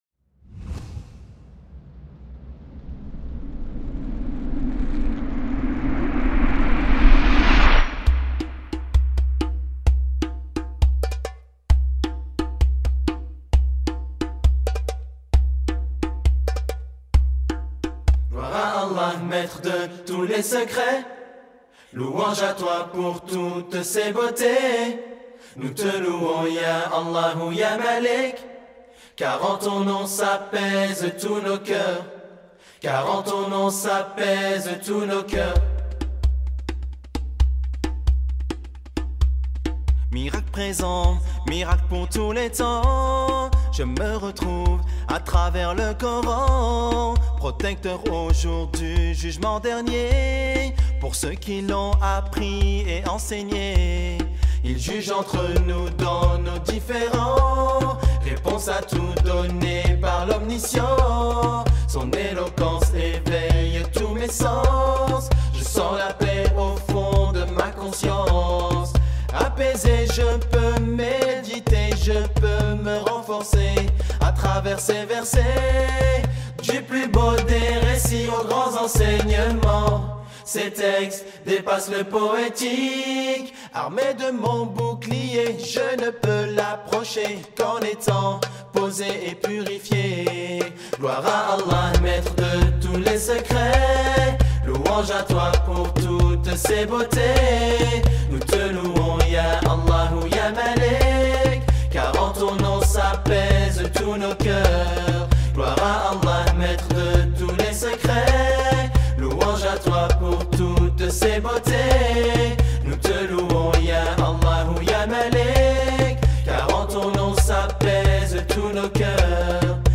Islam chant anachid